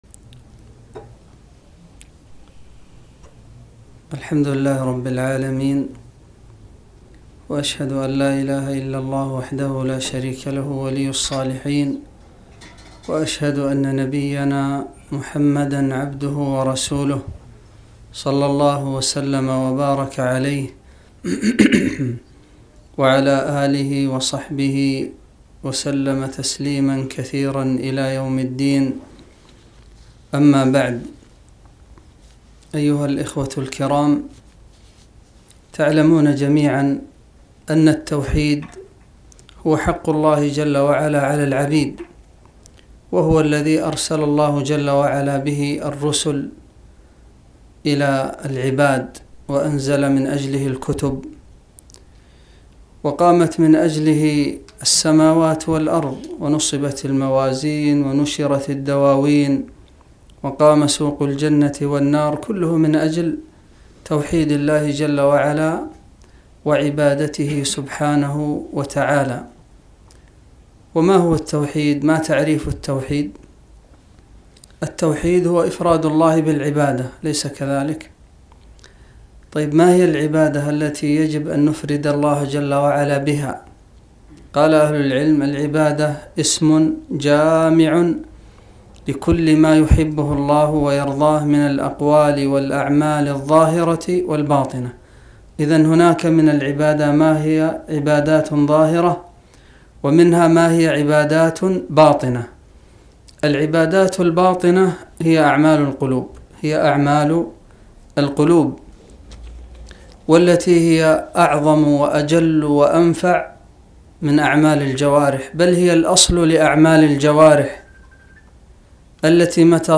محاضرة - حسن الظن بالله